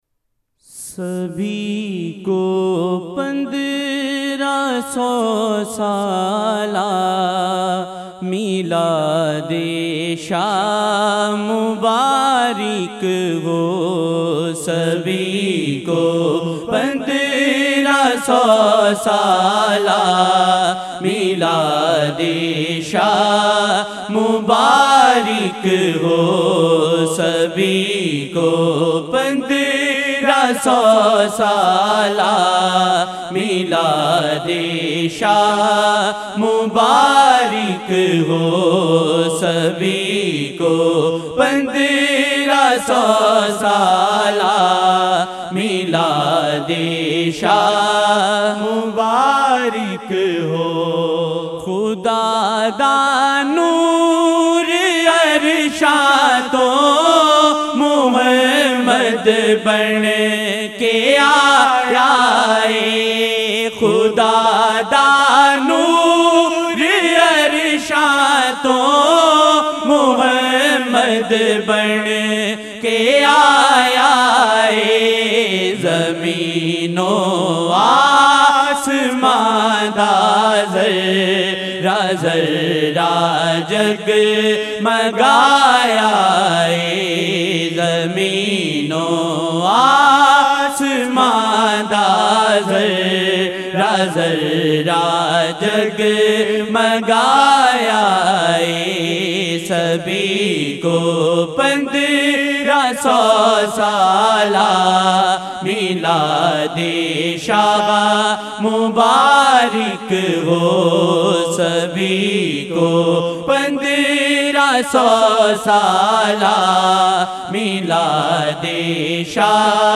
New Naat Shareef